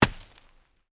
Heavy (Running)  Dirt footsteps 3.wav